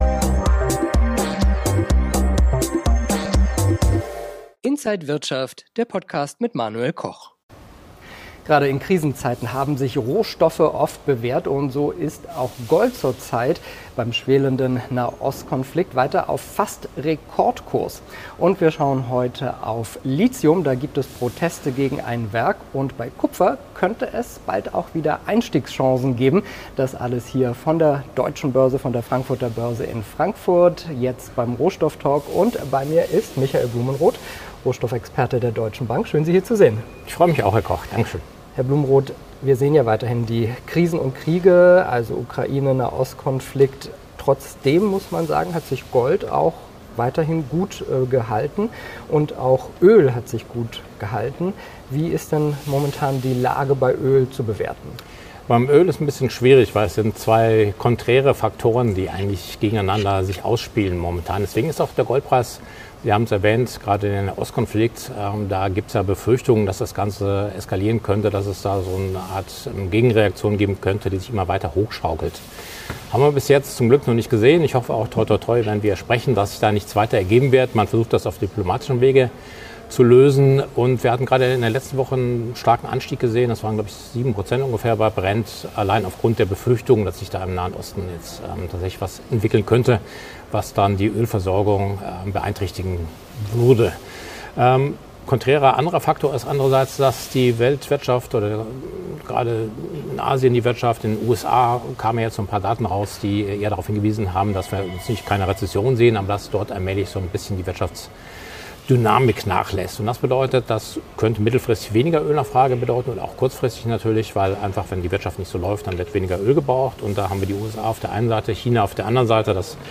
an der Frankfurter Börse